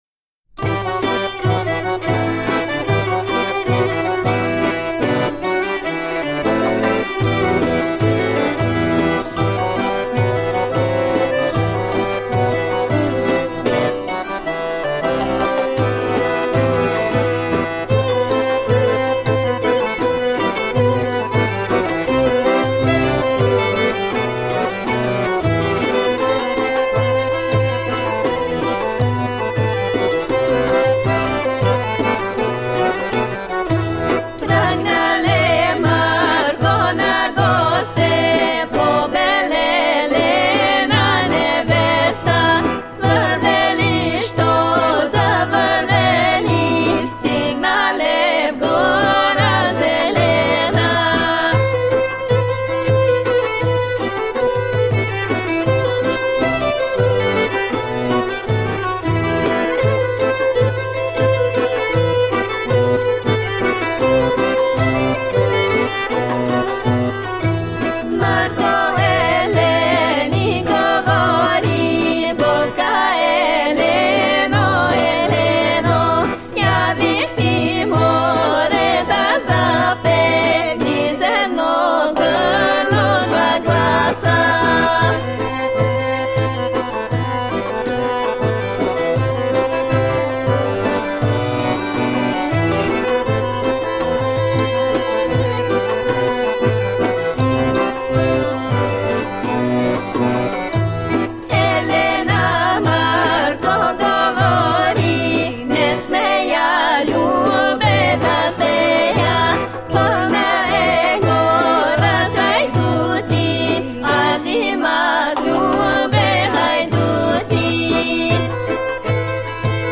Eastern and western european folk music..